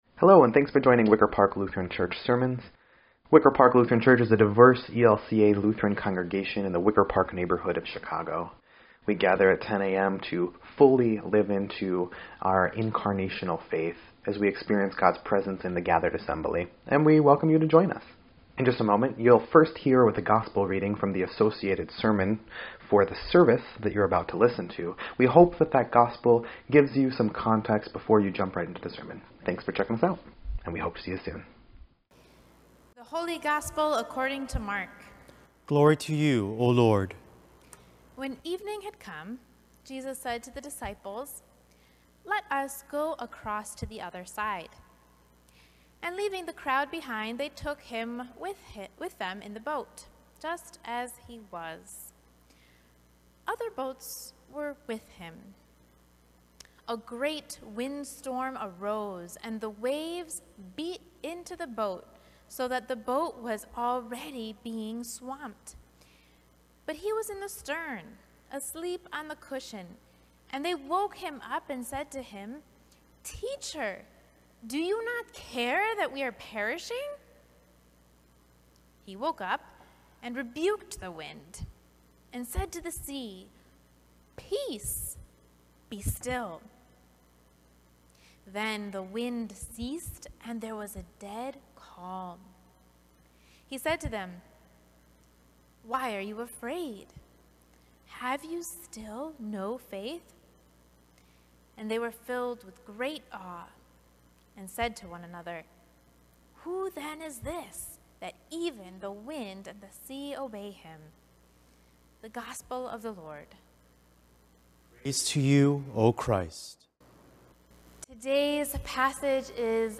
6.20.21-Sermon_EDIT.mp3